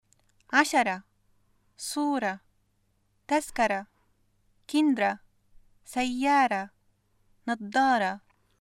ـ ـ ـرة の発音は -ra が基本ですが、まれに -re と発音することもあります。
[ʕaʃara, Suura, tazkara, kəndara, sayyaara, naDDaara]